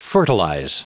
Prononciation du mot fertilize en anglais (fichier audio)
Prononciation du mot : fertilize